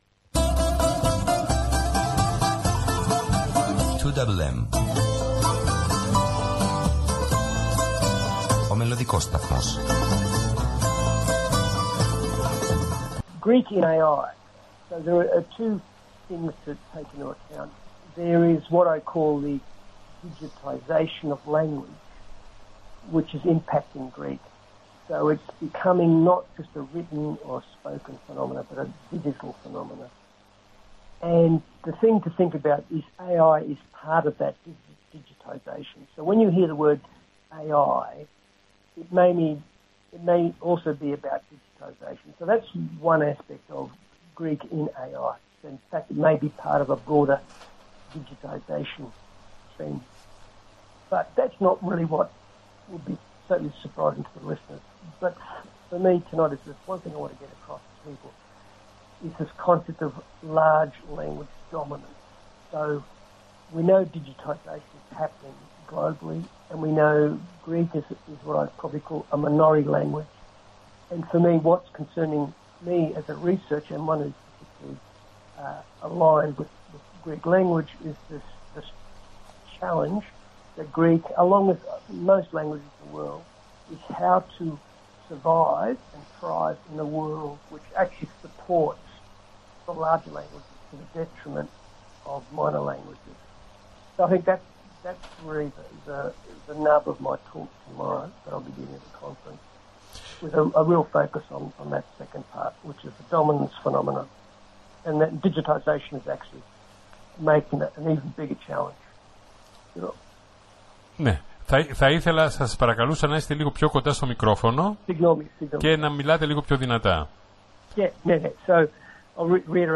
του ελληνόφωνου ραδιοσταθμού https